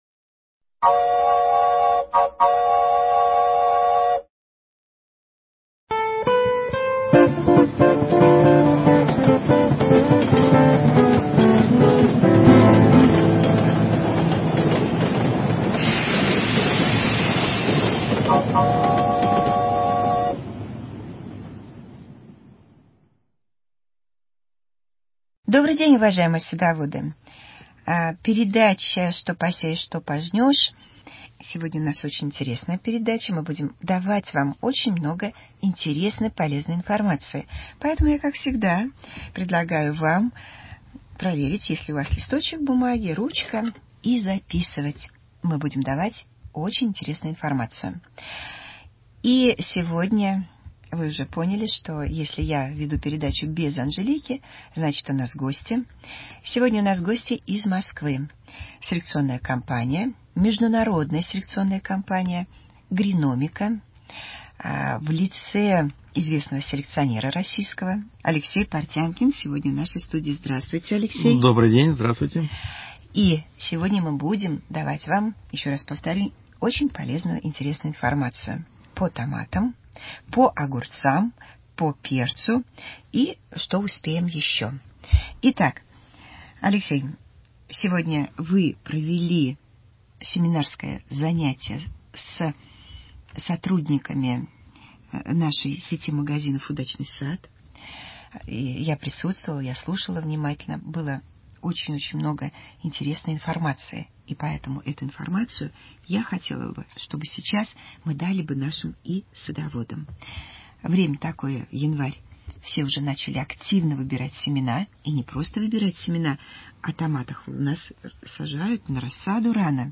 Передача для садоводов и огородников.